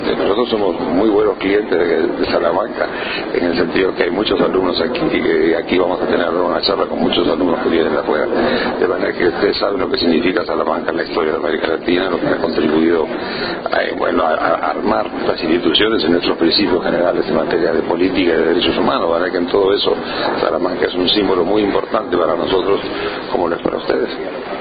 Enrique V. Iglesias comenta los vínculos entre Iberoamérica y la Universidad de Salamanca